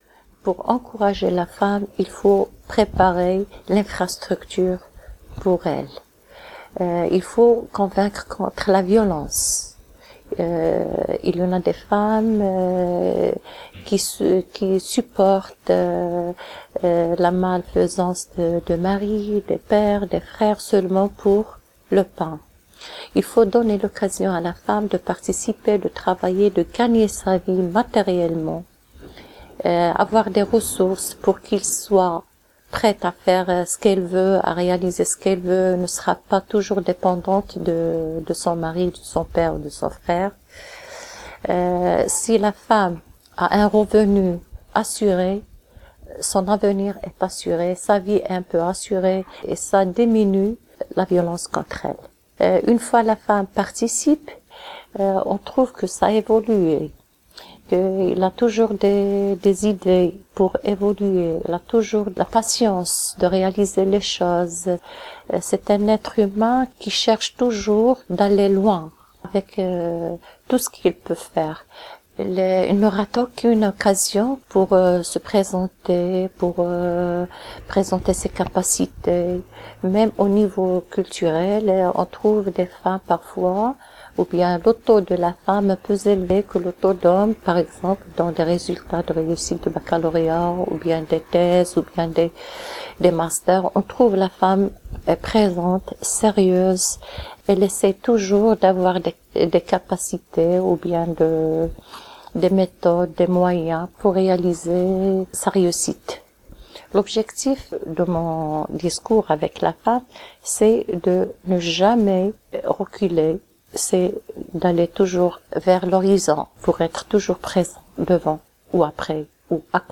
Ces portraits sonores radiophoniques sont un des volets de cette aventure artistique menée aux quatre coins du monde, la partie émergée de leur monde intérieur, une petite parcelle de ce qu'elles vivent, de ce qu'elles aiment, et de ce dont elles rêvent.